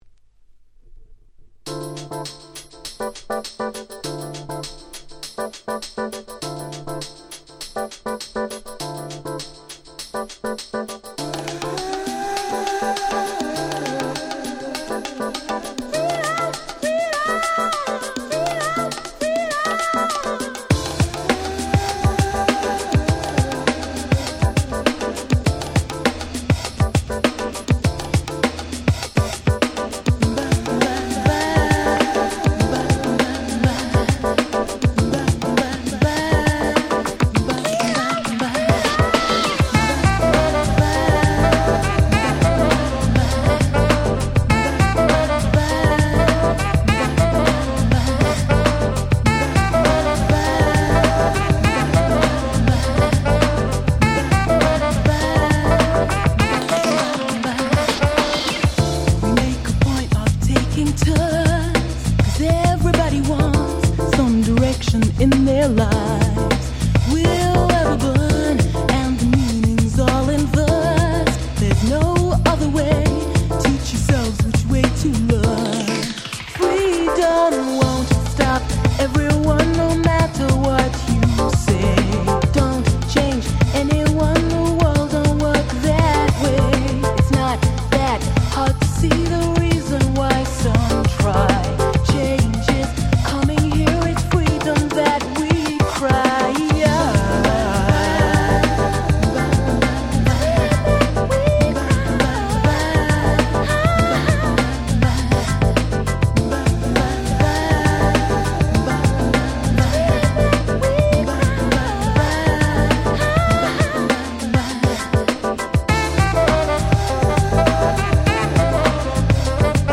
92' Super Nice UK R&B / UK Street Soul / Ground Beat !!
マニアにはお馴染みの超格好良いマイナーUK Street Soul !!
サビのラッパの音色が超Cool !!
グランド グラビ グラウンドビート 90's